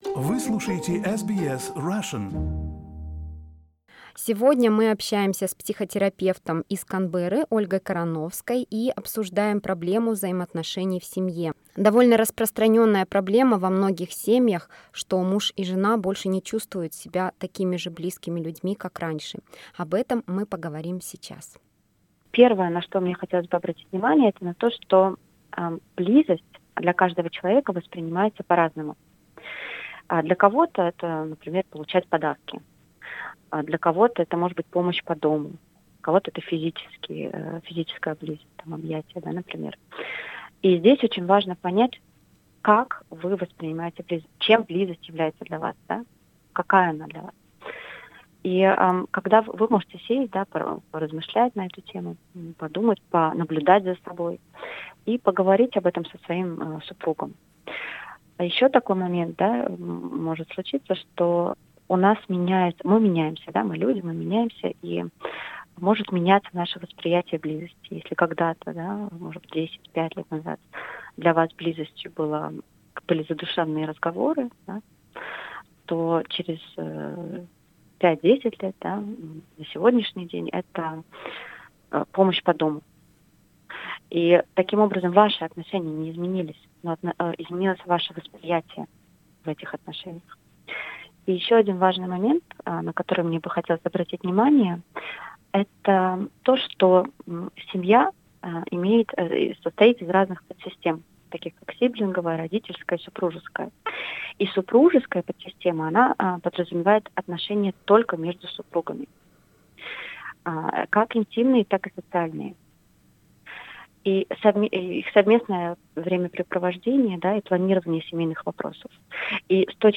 Как простить слова, сказанные в сердцах, как вернуть бабочек, и почему, живя много лет в браке, супруги уже не так близки, как прежде - ответы на эти и другие вопросы вы узнаете из нашего разговора